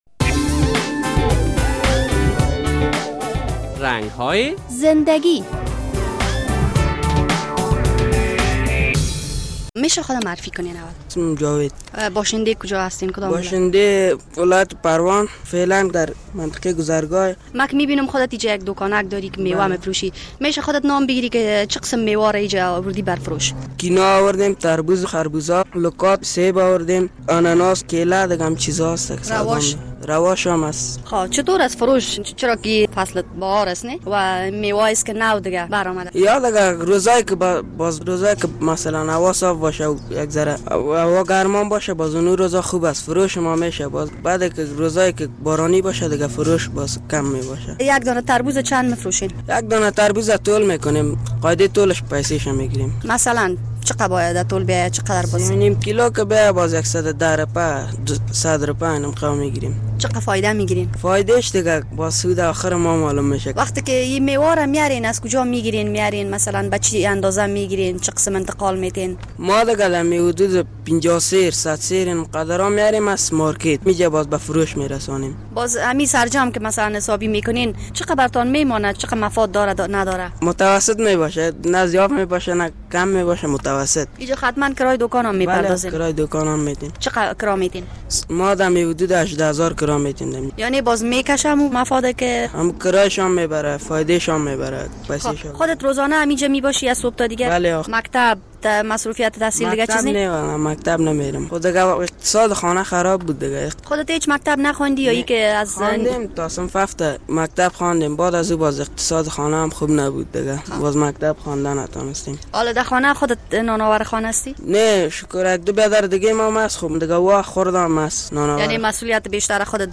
افغانستان یک کشور زراعتی است و از شهر کابل با داشتن بازار خوب، در هر چهار فصل سال میوه های مختلف را می توان پیدا کرد. در این برنامه یک دوکاندار میوه فروش را با خود داریم بشنوید که چه گفتنی ها دارد: